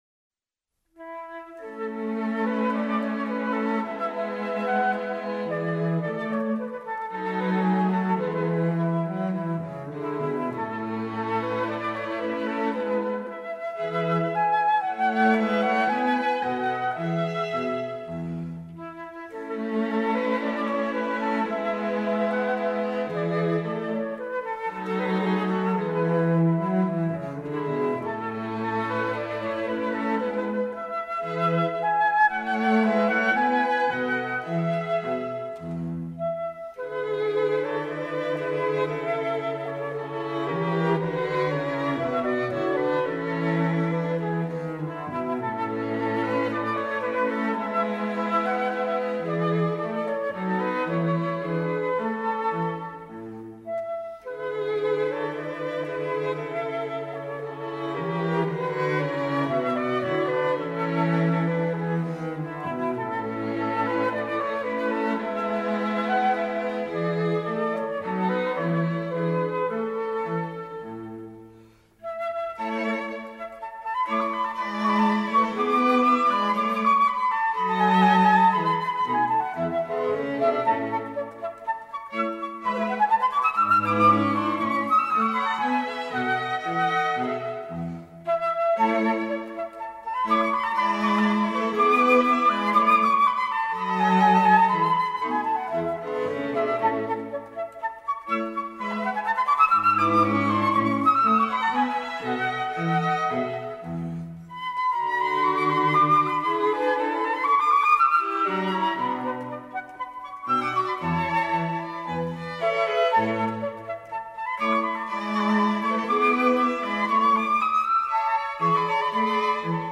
谢谢楼主....轻快极适合小朋友